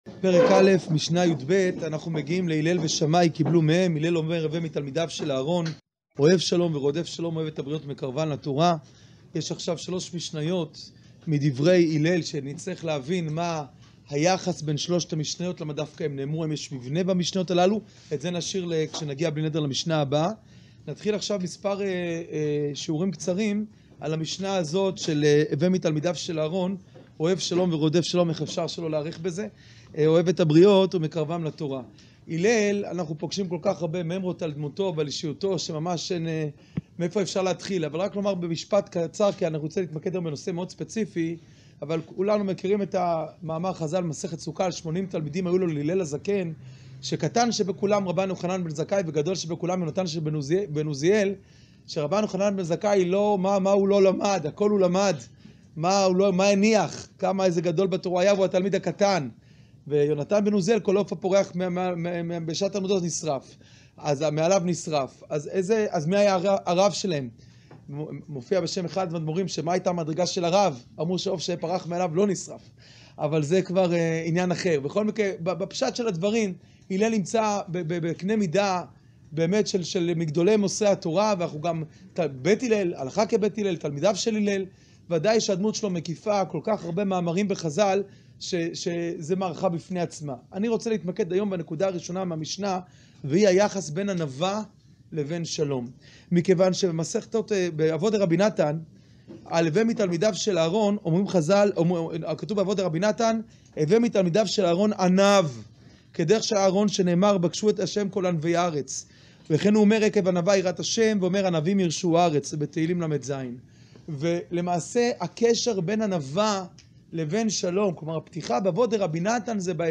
שיעור פרק א משנה יב